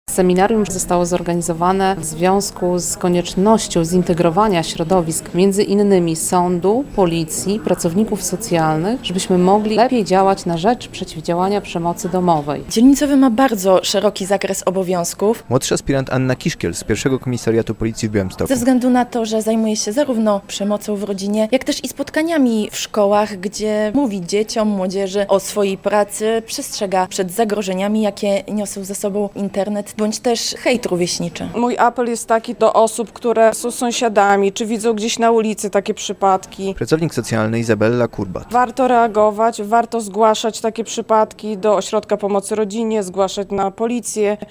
Seminarium szkoleniowe w Oddziale Prewencji Policji w Białymstoku - relacja